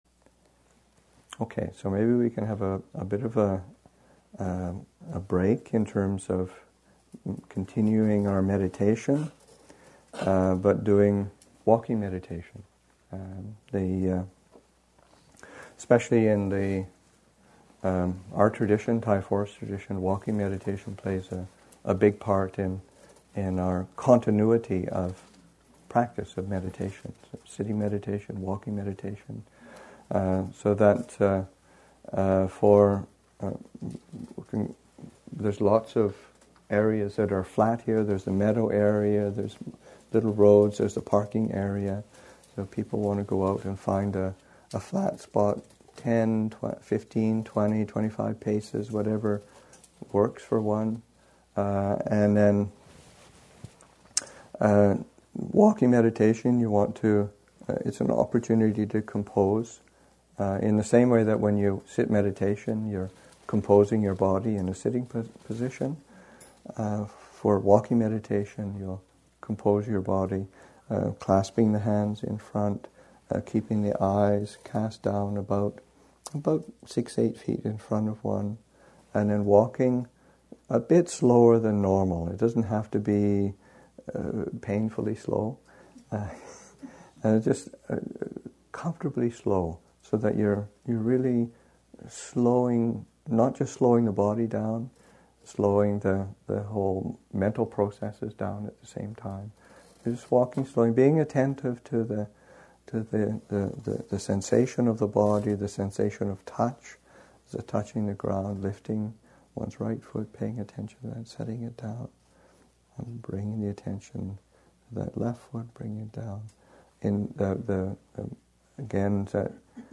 5. Meditation instruction: Walking meditation instructions.